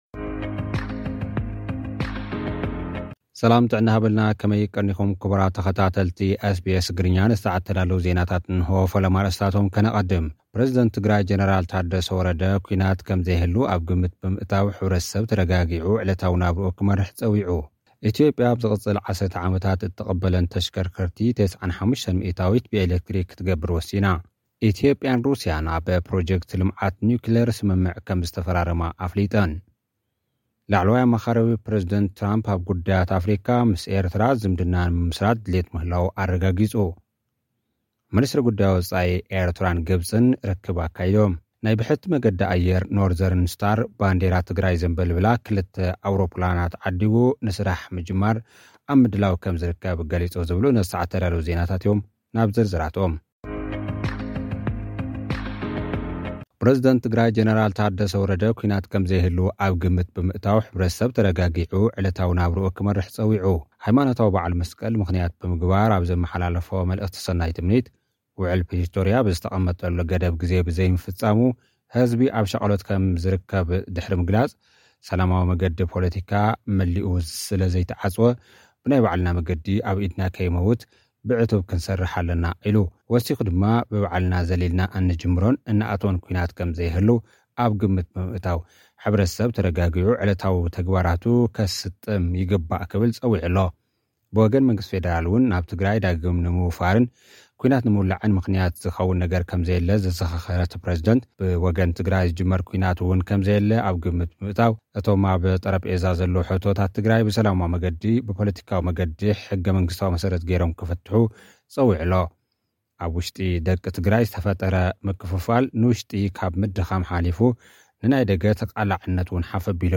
መንገዲ ኣየር ኖርዘርንስታር ባንዴራ ትግራይ ዘምበልብላ ክልተ ኣውሮፕላናት ዓዲጉ ስራሕ ንምጅማር ኣብ ምድላዋት ምህላው ገሊጹ። (ጸብጻብ)